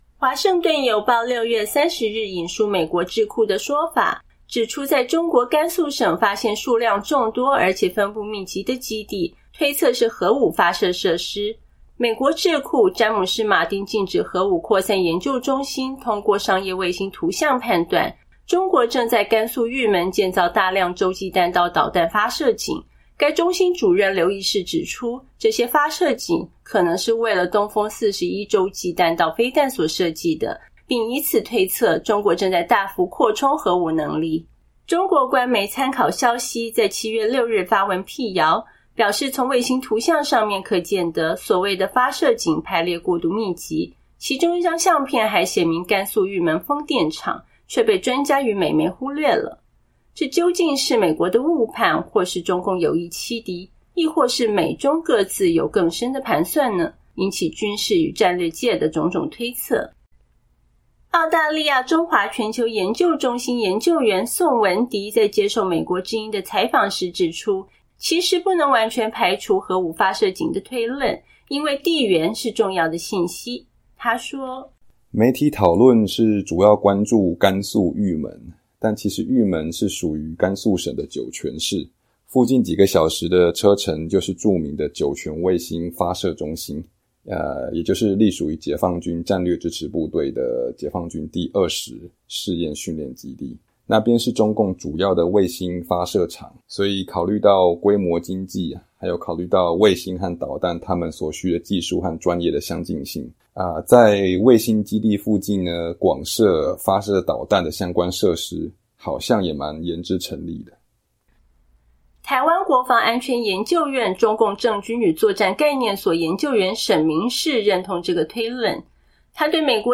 中国官媒日前对美国智库依据卫星照片判断甘肃省建有大量核武发射井的说法进行了驳斥，指出这其实是风力发电厂。接受美国之音采访的有关专家从不同角度分析了这个消息的真伪。